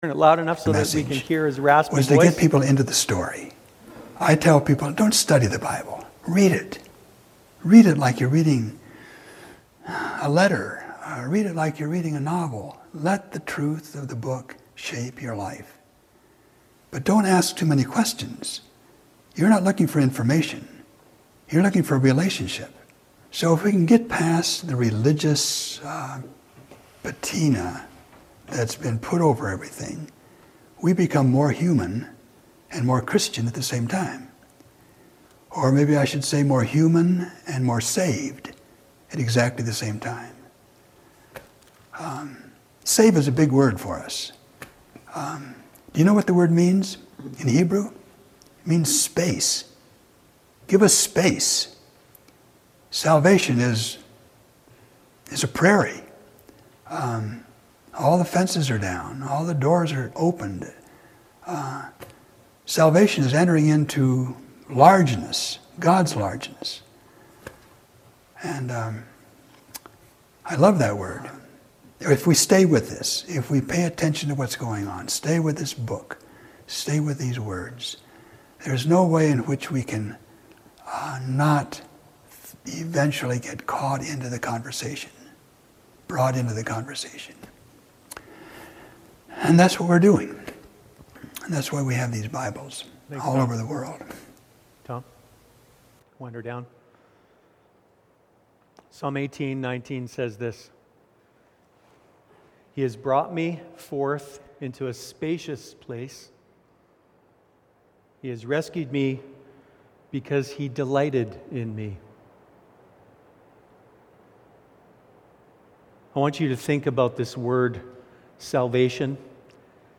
Sermons | The River Church